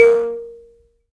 Index of /90_sSampleCDs/Roland LCDP11 Africa VOL-1/PLK_Buzz Kalimba/PLK_HiBz Kalimba